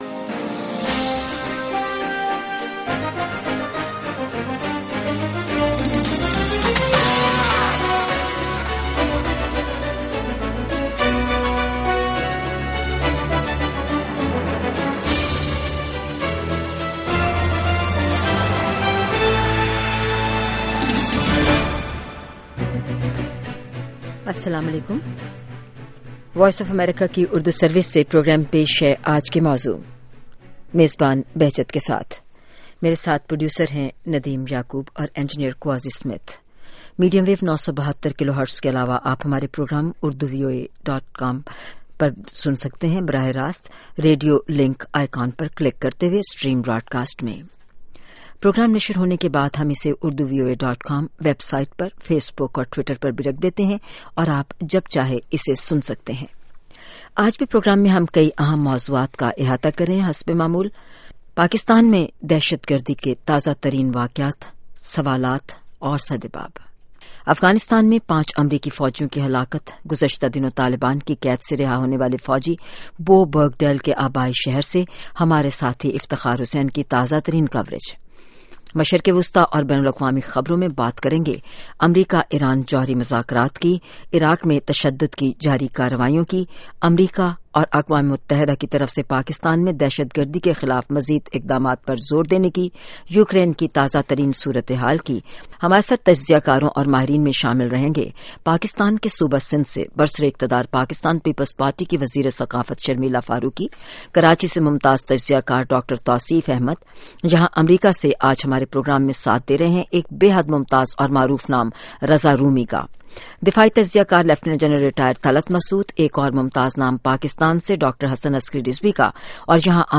Sound Bites